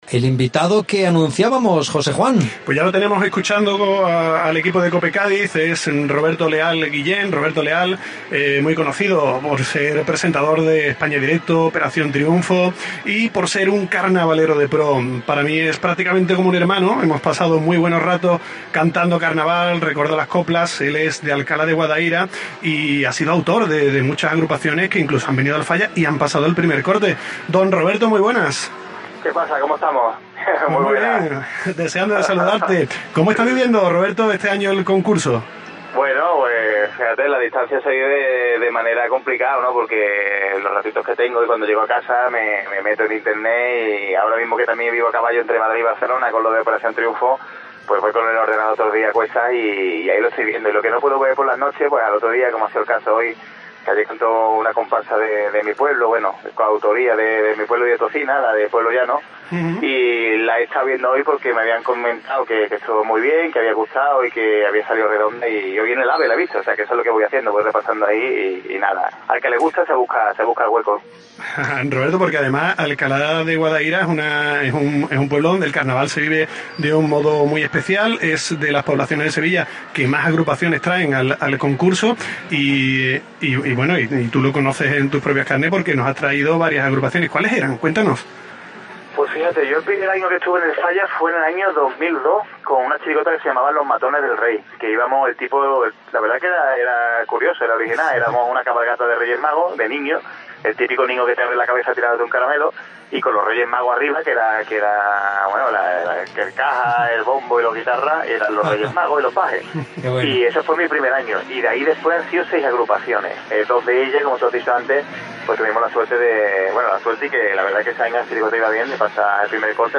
Roberto Leal, presentador de OT y carnavalero, en COPE
ESCUCHA LA ENTREVISTA Contenidos relacionados Roberto Leal, presentador de OT y carnavalero, en COPE